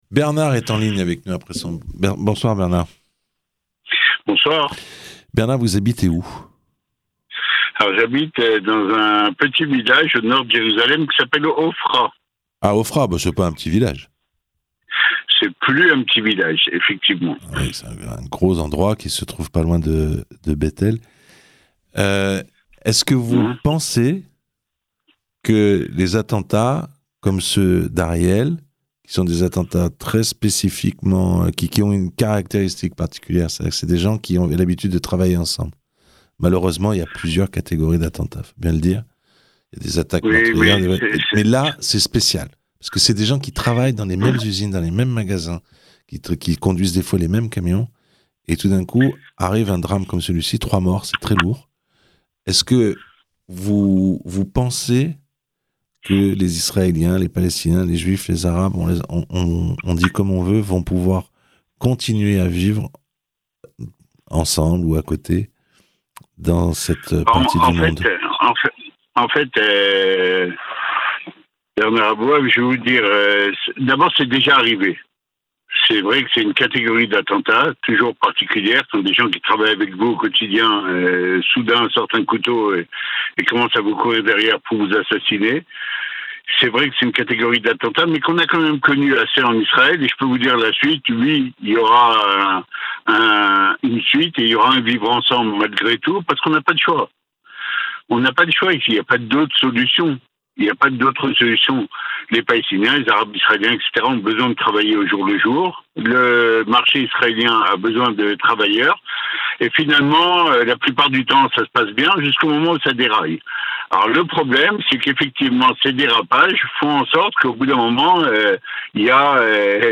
Témoignages d'israéliens habitant les territoires.